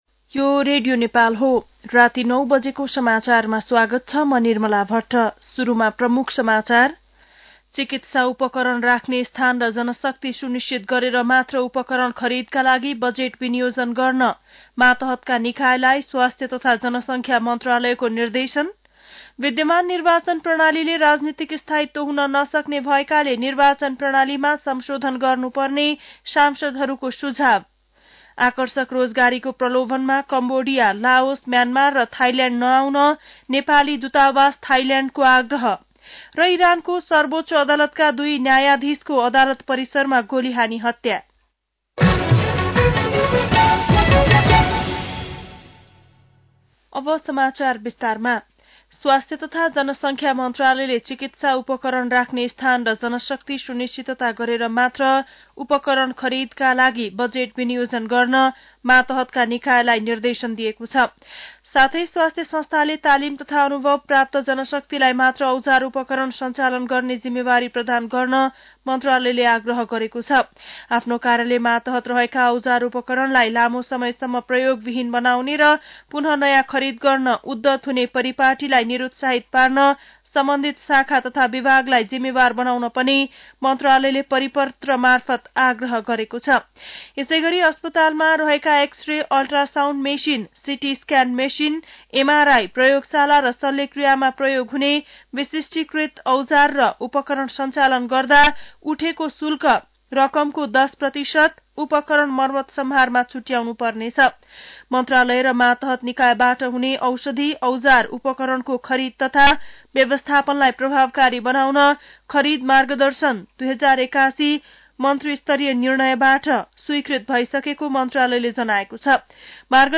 9-PM-Nepali-News-10-5.mp3